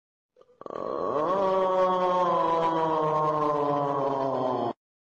Plankton Aughhhh Sound Effect Free Download